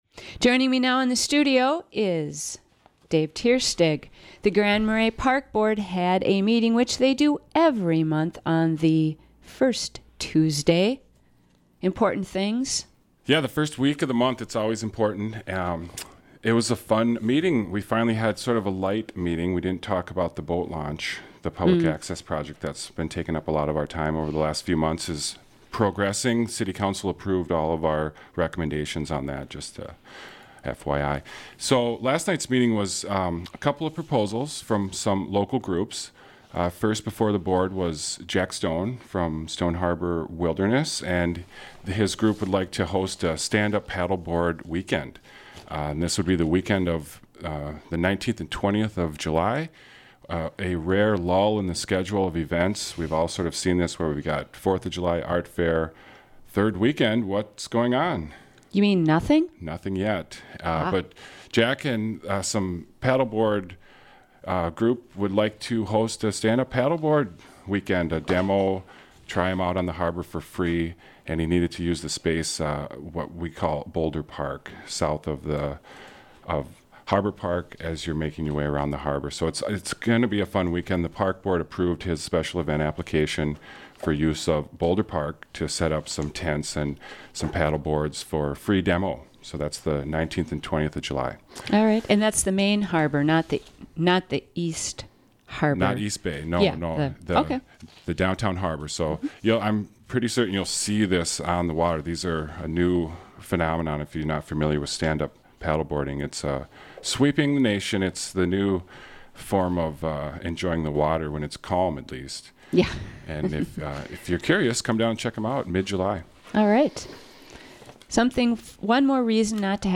News & Information